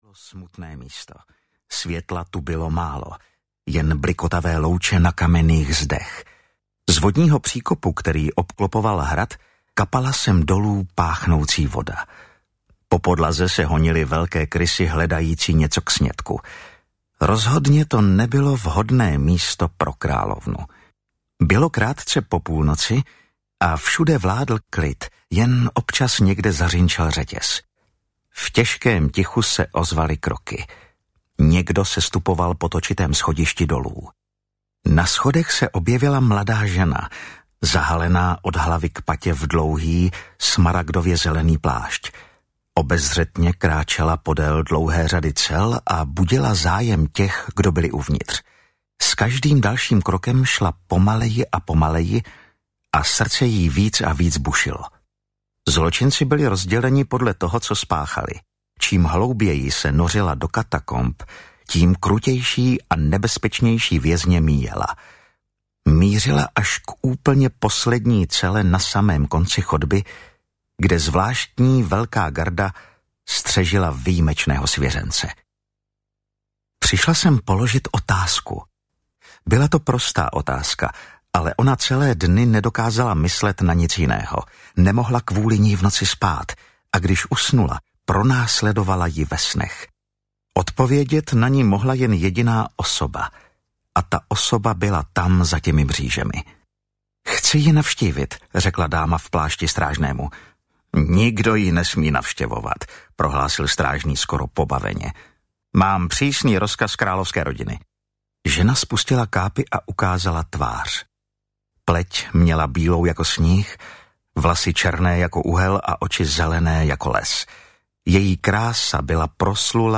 Země příběhů - Kouzelné přání audiokniha
Ukázka z knihy